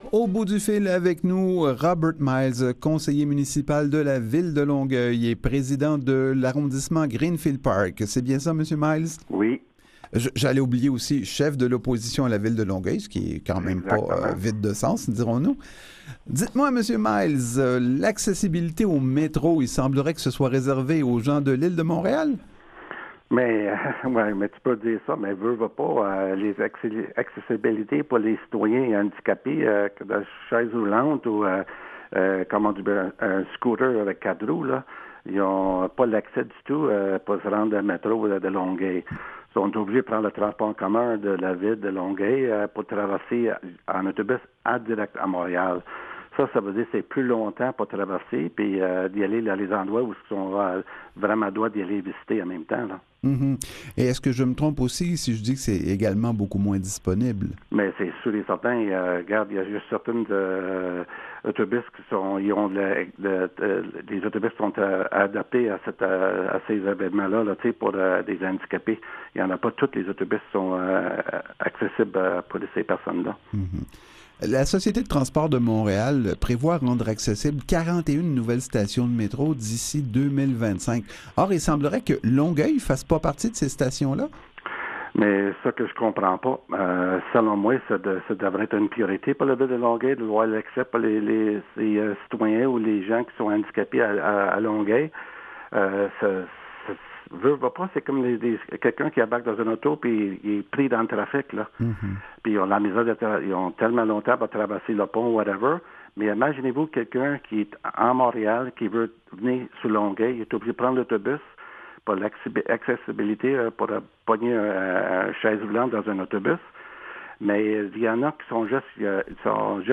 L’ENTREVUE DU JOUR
Avec Robert Myles, chef de l'opposition à la ville de Longueuil. —